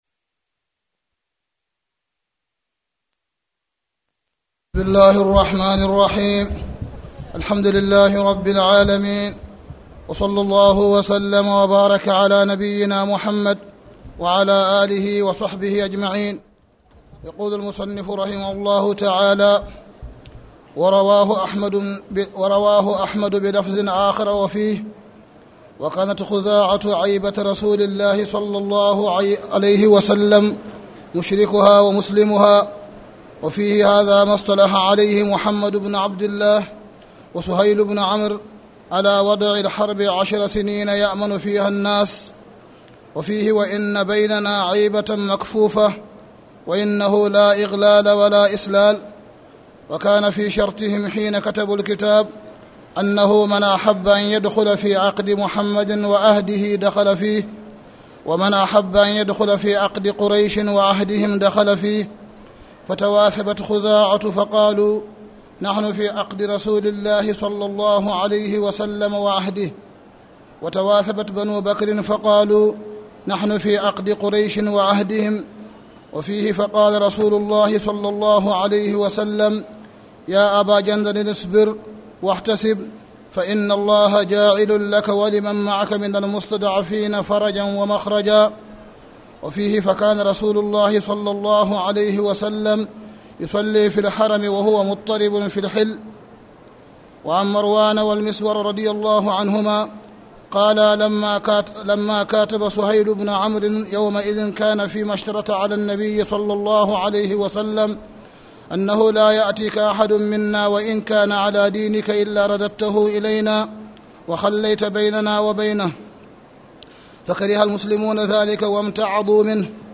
تاريخ النشر ٢٨ شعبان ١٤٣٨ هـ المكان: المسجد الحرام الشيخ: معالي الشيخ أ.د. صالح بن عبدالله بن حميد معالي الشيخ أ.د. صالح بن عبدالله بن حميد باب ما يجوز من الشروط مع الكفار